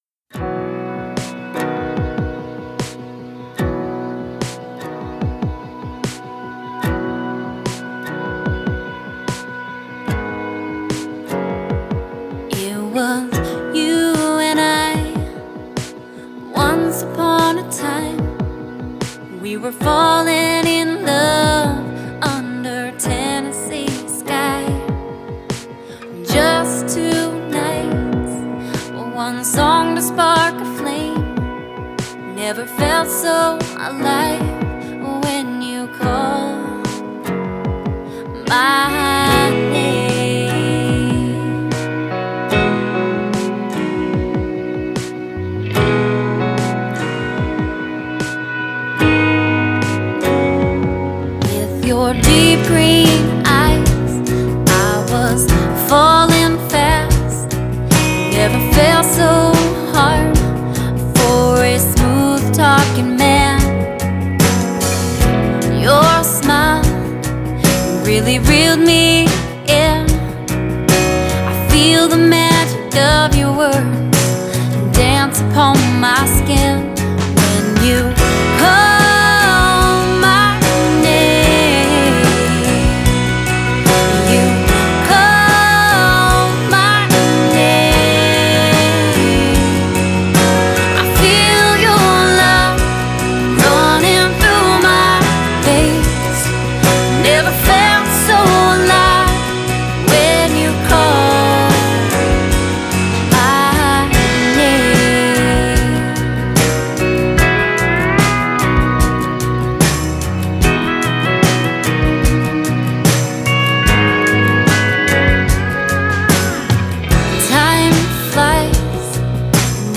28 min of Western Canadian indie music mix